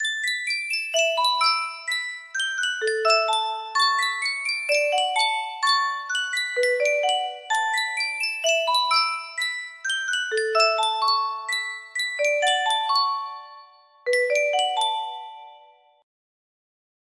Type Full range 60
BPM 128